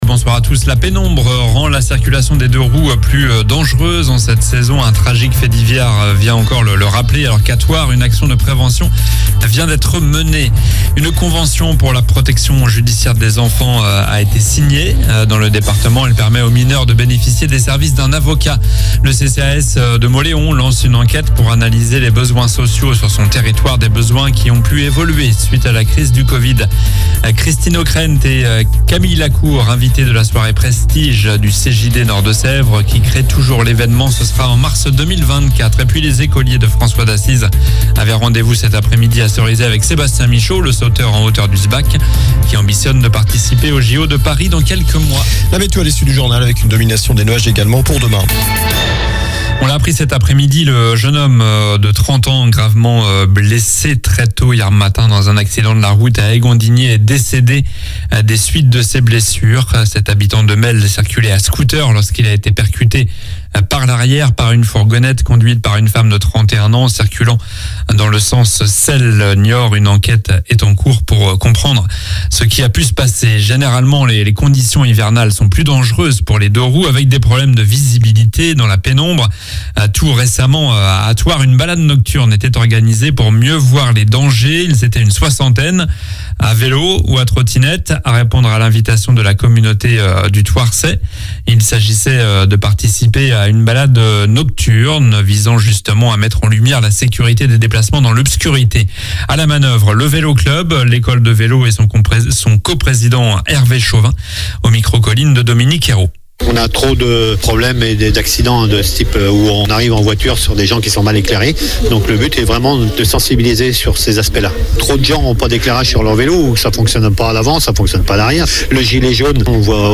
Journal du jeudi 30 novembre (soir)